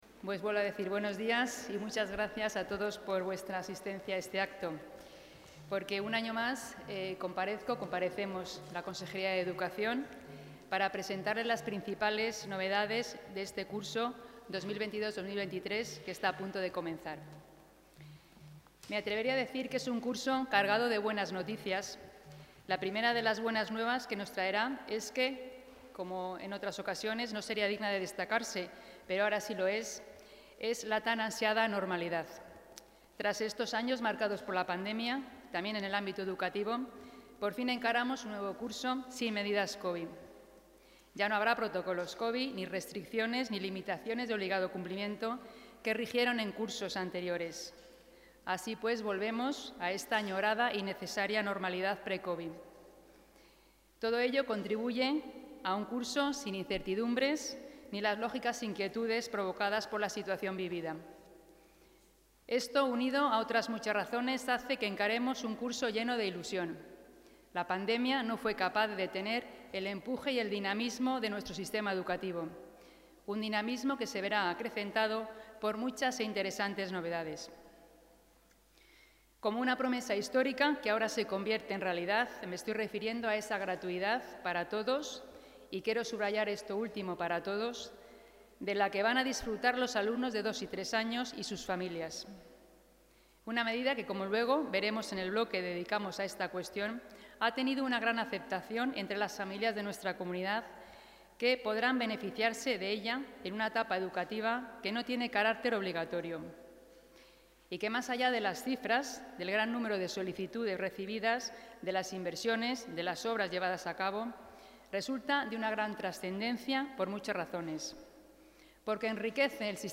Intervención de la consejera de Educación.